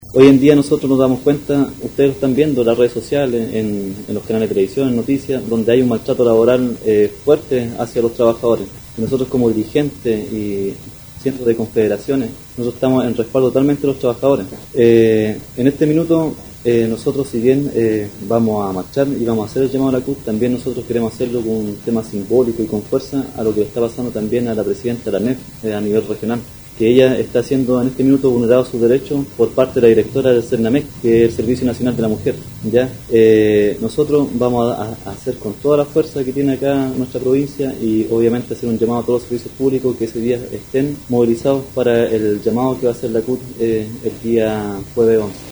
En la oportunidad, se llevo a cabo una conferencia de prensa en dependencias del Colegio de Profesores, donde estaban presentes también funcionarios de la salud pública, dirigentes de los trabajadores de las direcciones provinciales de educación, de la industria del salmón, y de manera muy especial, los empleados de centros comerciales, quienes si bien están desde hace mucho tiempo asociados a la CUT, es primera vez que se muestran apoyando firmemente el movimiento social convocado por la Central Unitaria para el día 11 de abril.